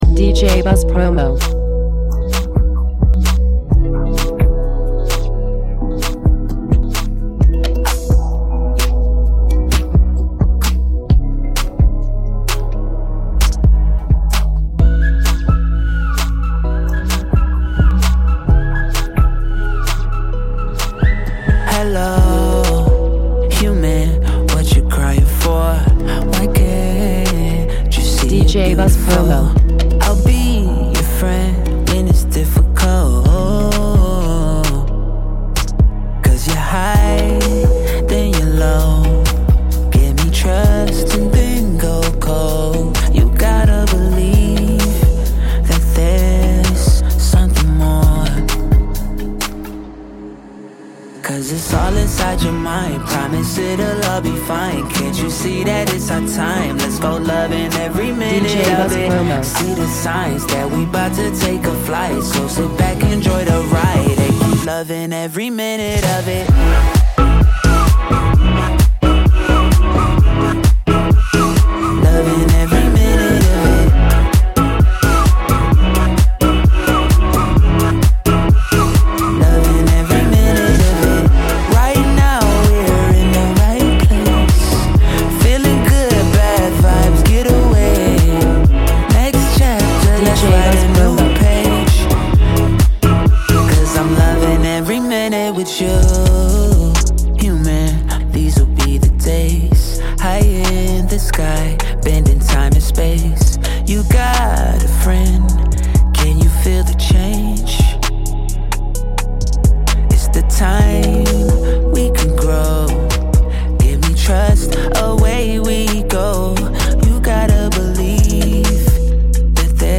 New soulful single
Radio Edit